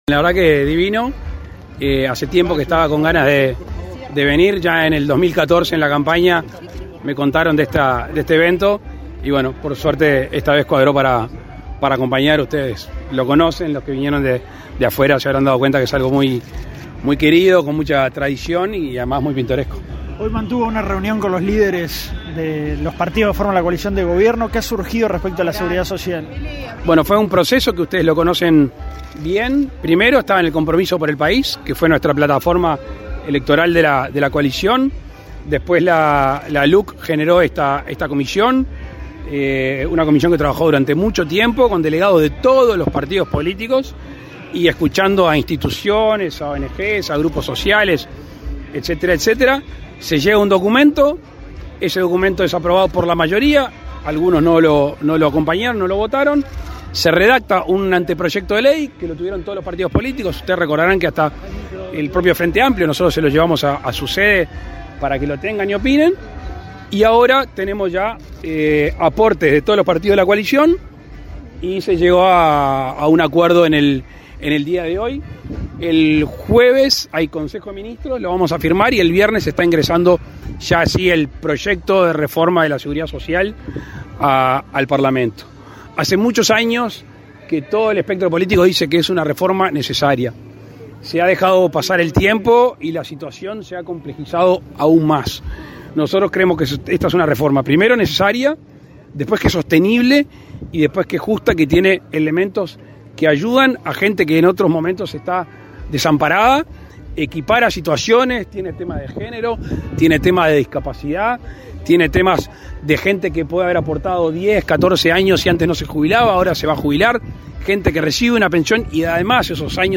Declaraciones a la prensa del presidente de la República, Luis Lacalle Pou
Declaraciones a la prensa del presidente de la República, Luis Lacalle Pou 18/10/2022 Compartir Facebook X Copiar enlace WhatsApp LinkedIn El presidente de la República, Luis Lacalle Pou, participó, este 17 de octubre, en el festejo por los 164 años de la fundación de la localidad de La Paz, en el departamento de Colonia. Luego el mandatario realizó declaraciones a la prensa.